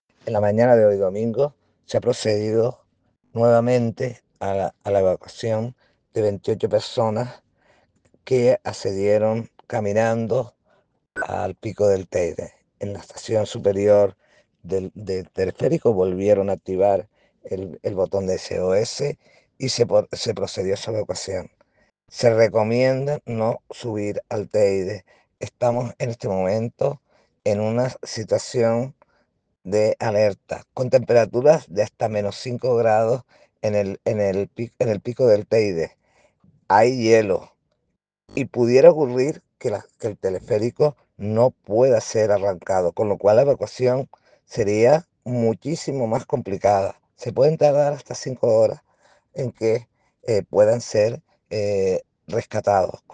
La consejera de Medio Natural, Sostenibilidad y Seguridad y Emergencias, Blanca Pérez, señala que “estas conductas son imprudentes y las personas están poniendo en riesgo su seguridad y la de las personas que tienen que intervenir en la emergencia.
Audio-Blanca-Perez-1.mp3